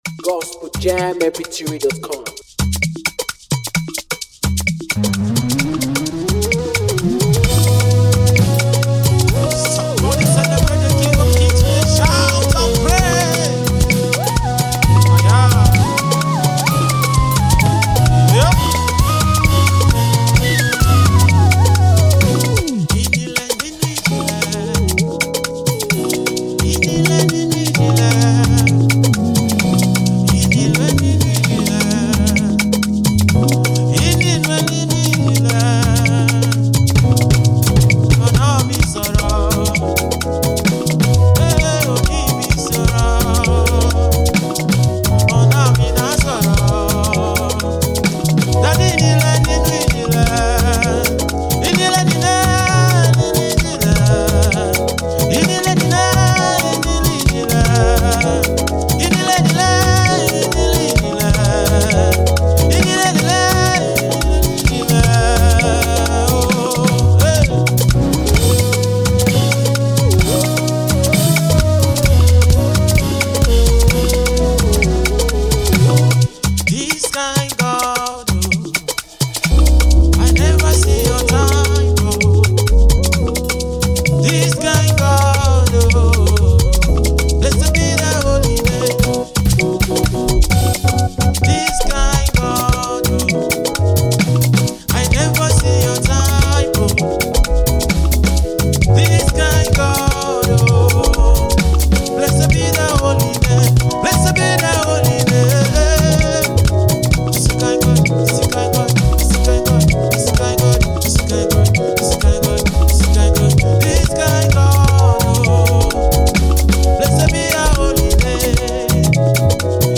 a song that will keep you dancing.